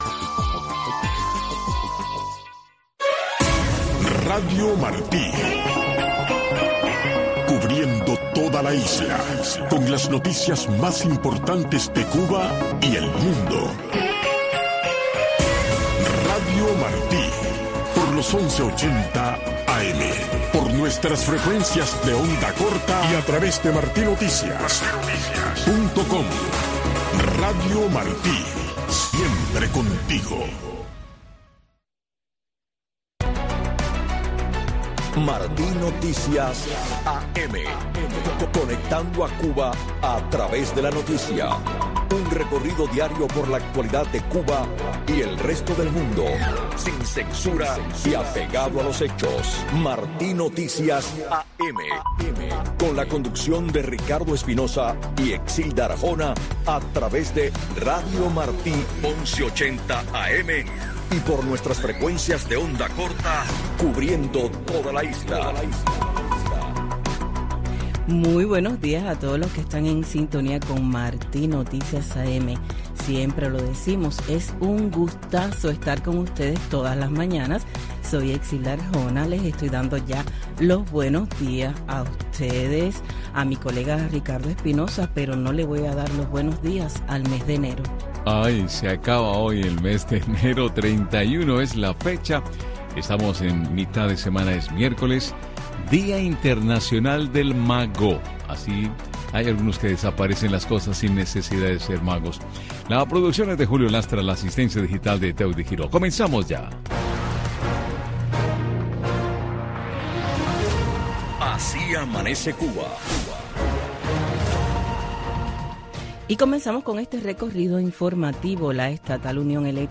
Revista informativa con los últimos acontecimientos ocurridos en Cuba y el mundo. Con entrevistas y temas de actualidad relacionados a la política, la economía y de interés general.